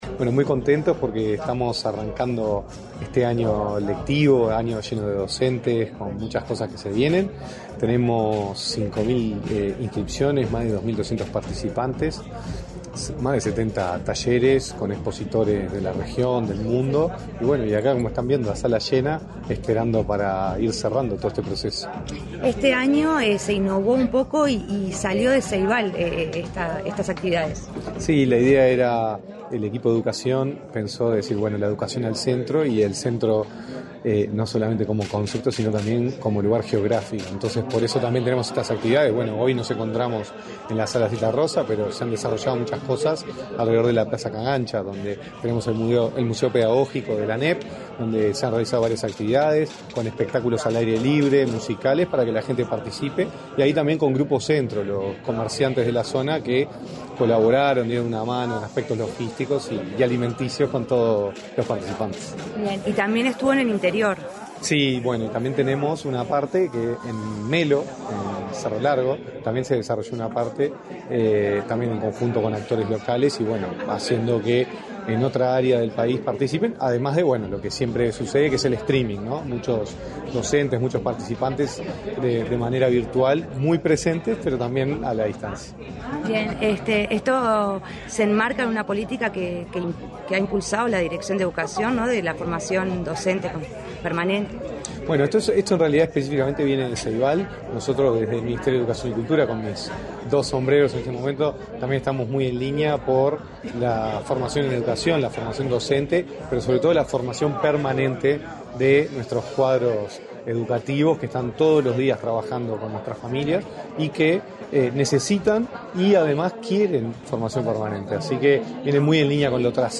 Entrevista al presidente de Ceibal, Gonzalo Baroni | Presidencia Uruguay
El presidente de Ceibal y director nacional de Educación, Gonzalo Baroni, dialogó con Comunicación Presidencial acerca del cierre de una nueva edición